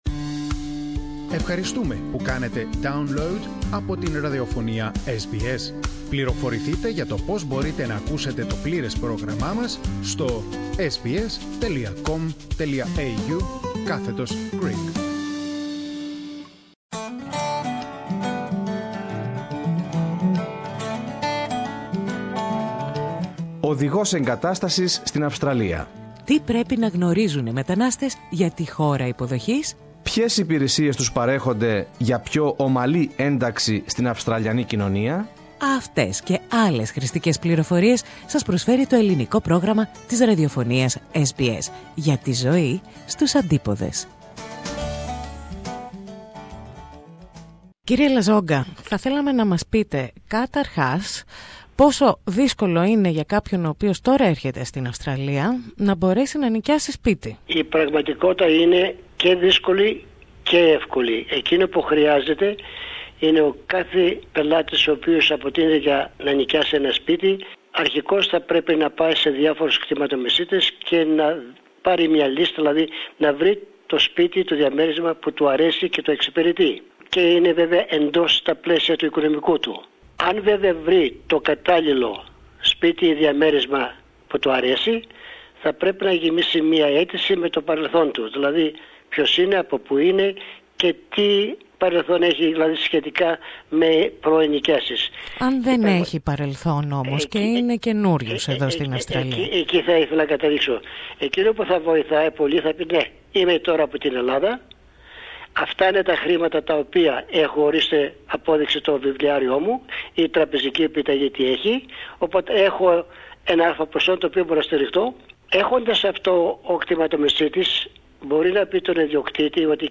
This interview is only available in Greek.